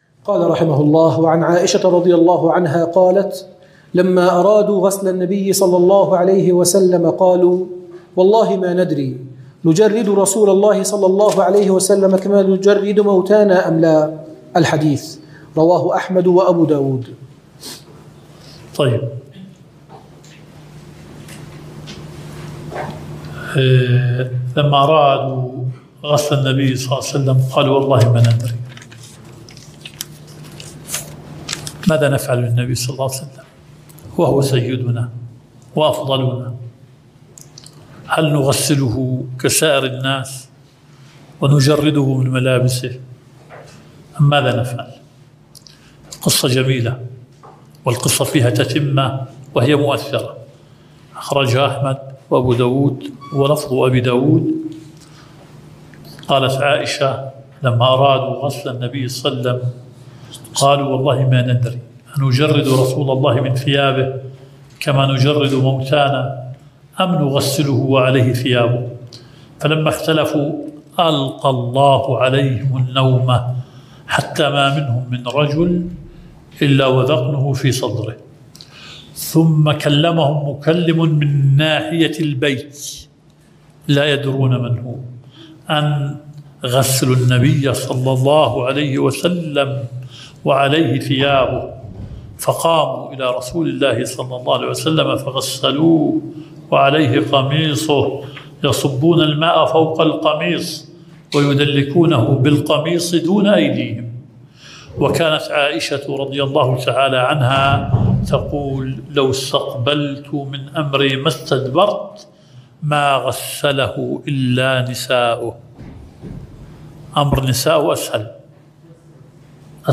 البث المباشر – المحاضرة الثانية 🔸شرح بلوغ المرام (باب صلاة العيدين – آخر كتاب الزكاة)